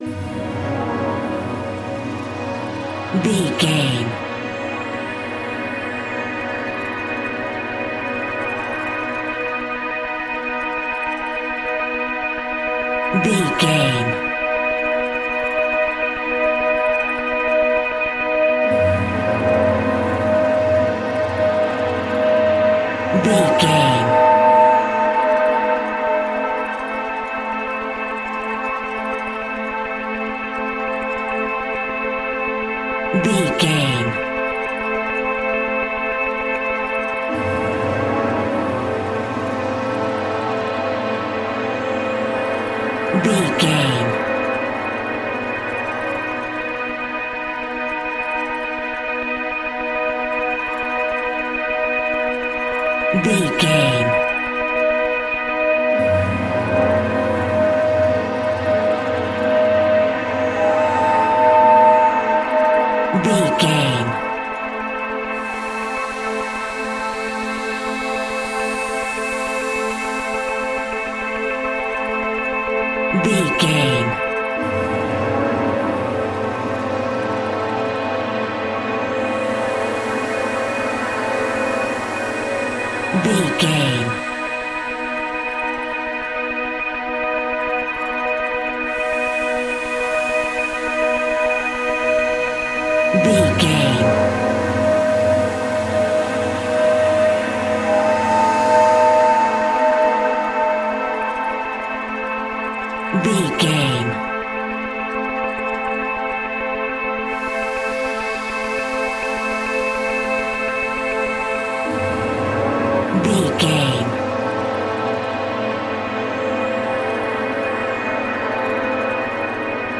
royalty free music
Aeolian/Minor
B♭
ominous
dark
haunting
eerie
synthesizer
horror music
Horror Pads
Horror Synths
suspenseful